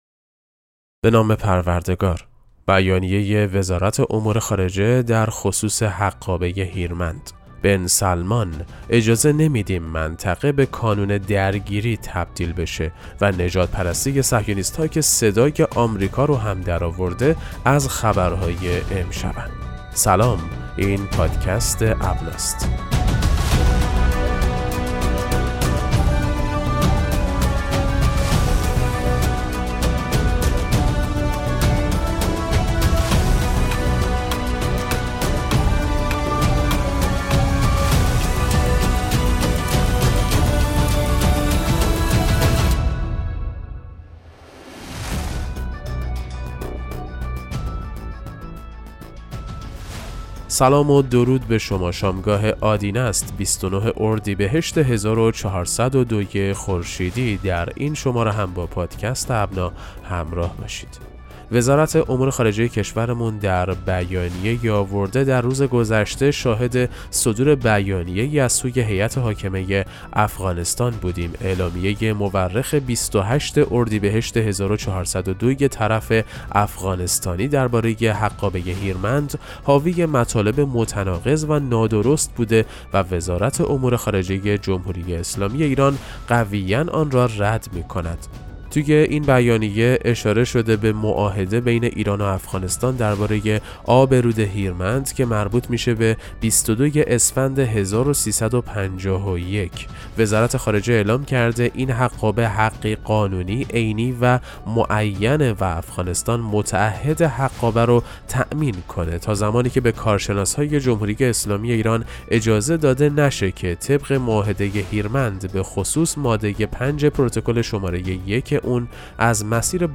پادکست مهم‌ترین اخبار ابنا فارسی ــ 29 اردیبهشت 1402